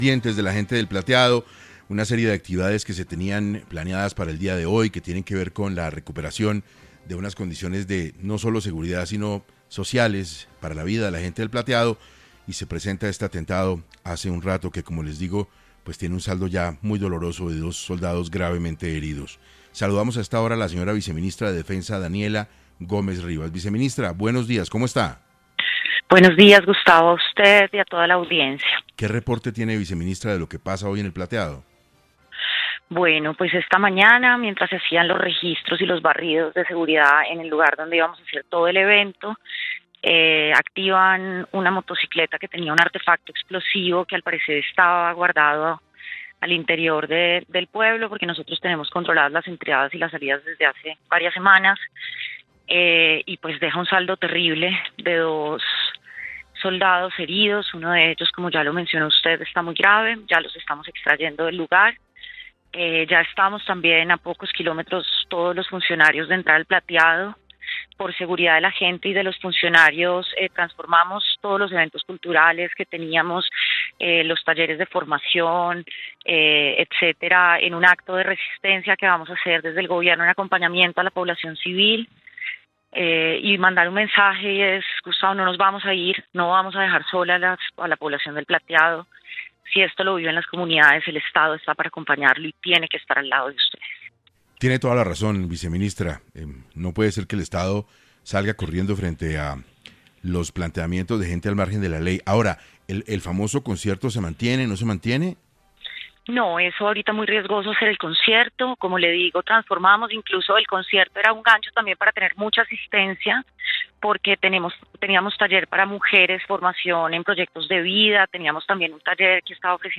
Radio en vivo
Daniela Gómez Rivas, viceministra de Defensa, hizo hincapié en 6AM sobre qué acciones están tomando ante los recientes ataques en la zona y sobre si habrá concierto o no